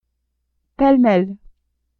pEle-mEle_French.mp3